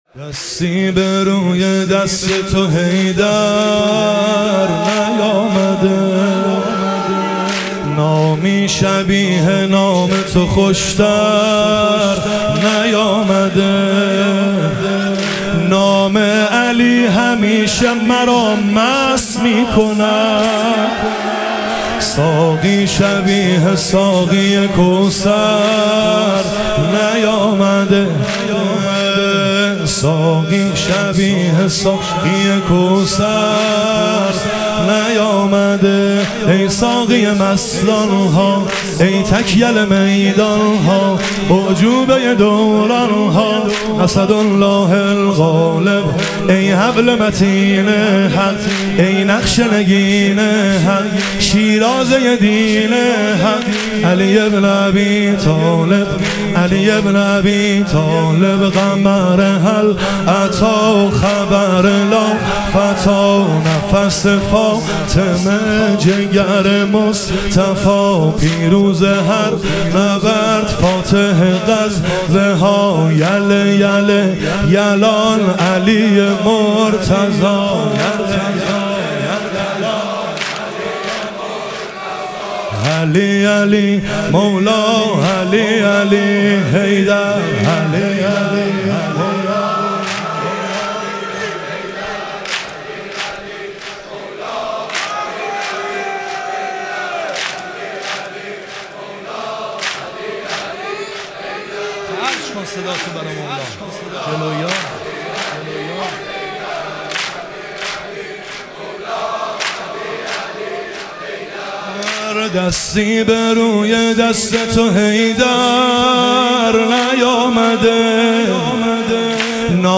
شهادت حضرت خدیجه علیها سلام - واحد